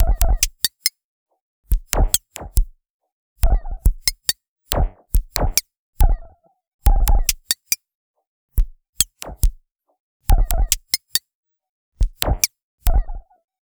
Abstract Rhythm 15.wav